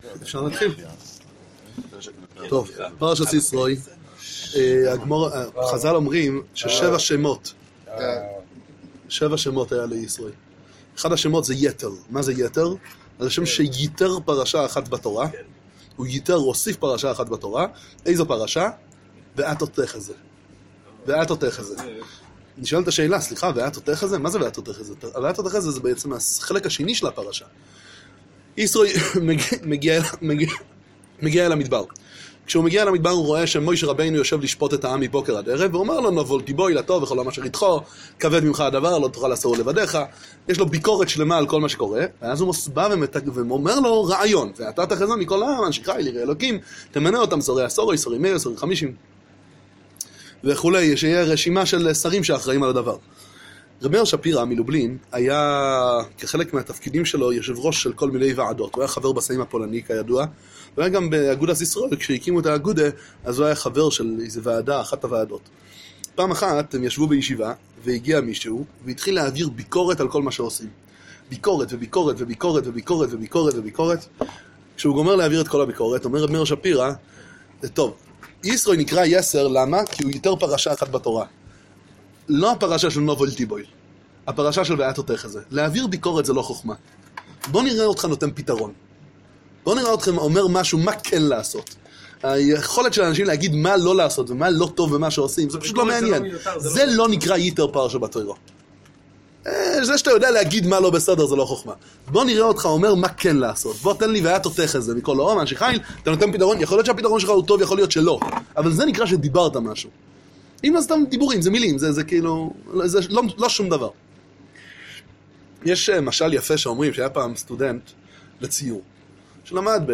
דבר תורה קצר